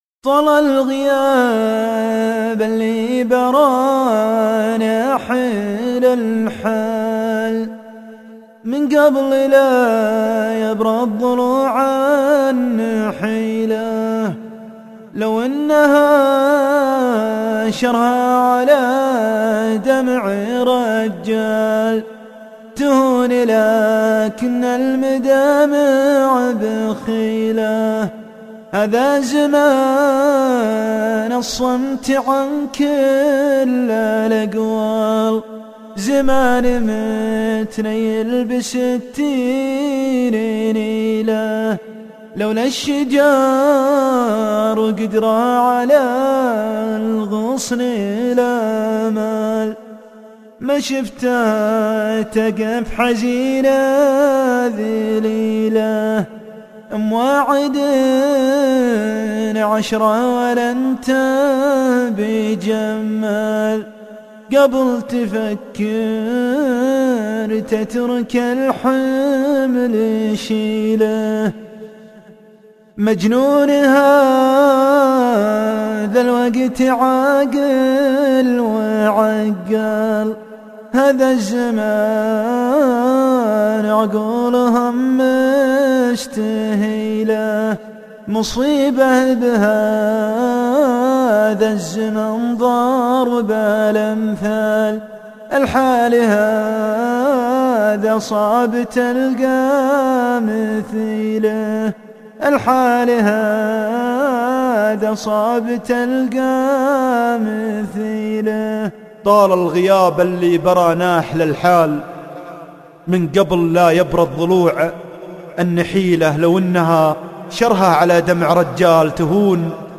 شيله+القاء